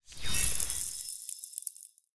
Free Frost Mage - SFX
ice_blade_08.wav